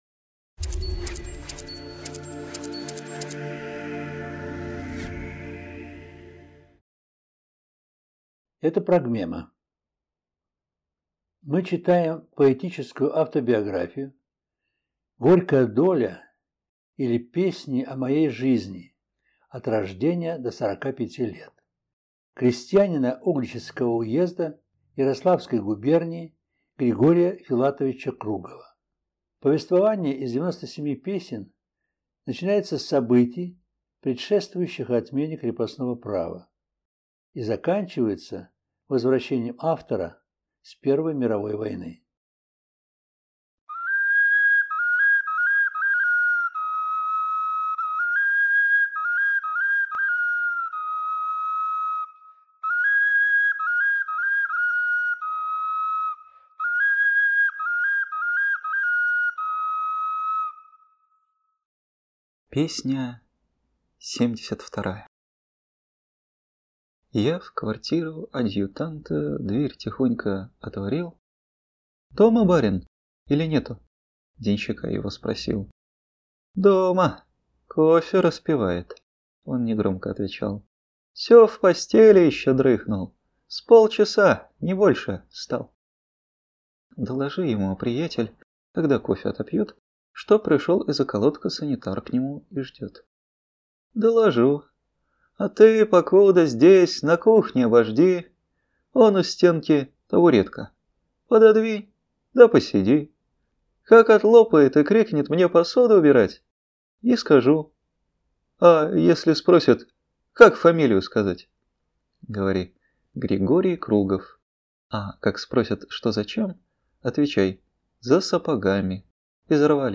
В записи использованы звучание глиняных Скопинских, Суджанских, Чернышенских игрушек-окарин